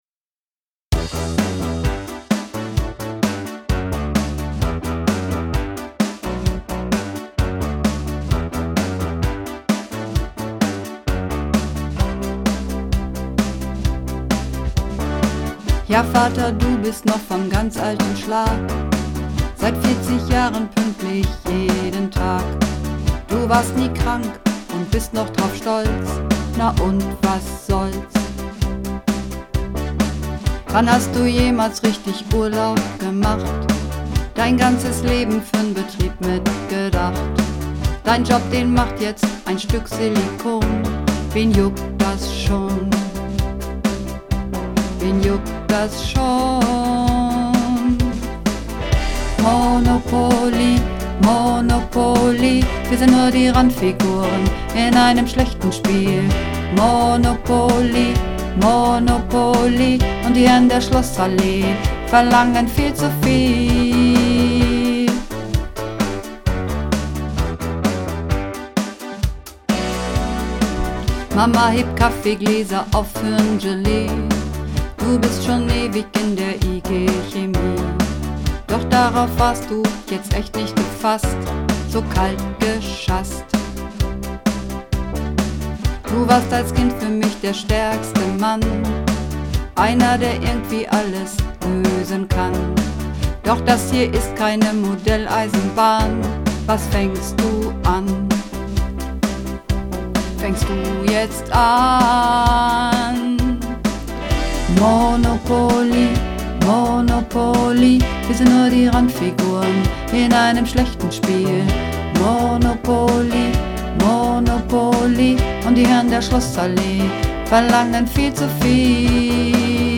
Übungsaufnahmen - Monopoli
Runterladen (Mit rechter Maustaste anklicken, Menübefehl auswählen)   Monopoli (Bass)
Monopoli__2_Bass.mp3